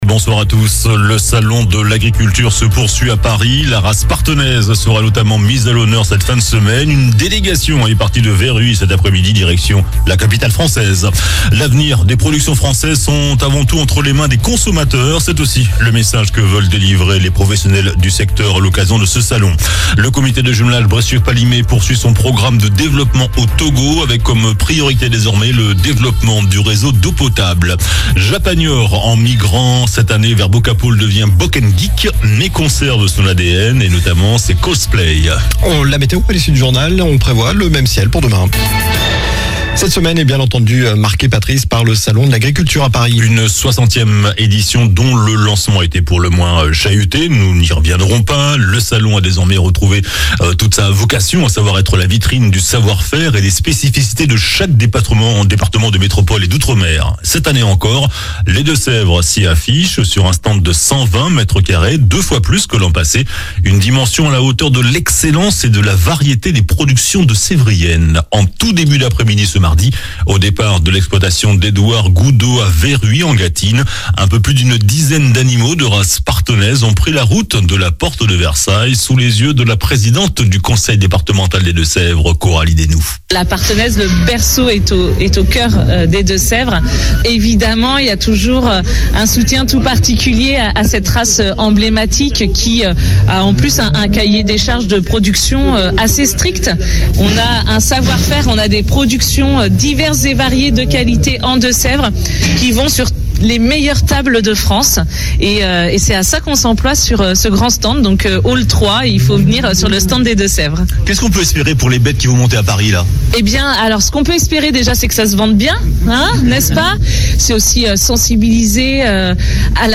JOURNAL DU MARDI 27 FEVRIER ( SOIR )